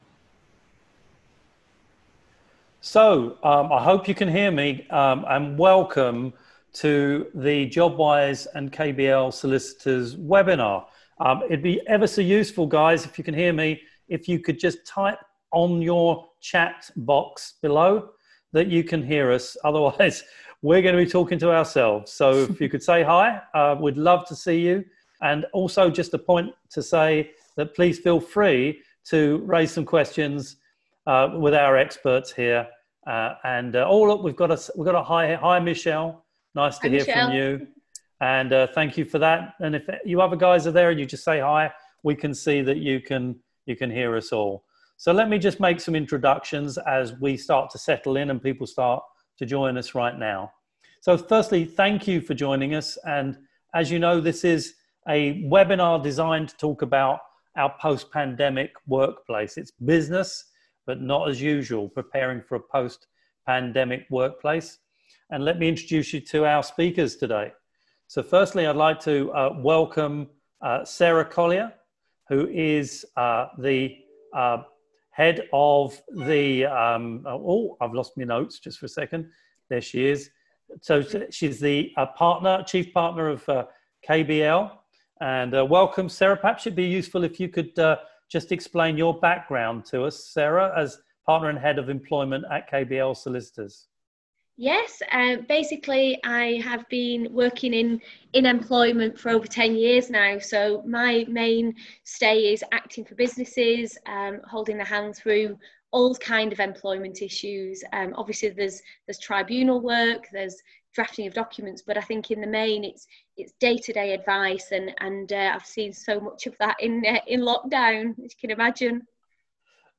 Listen to our Webinar: Preparing for a Post Pandemic Workplace - KBL Solicitors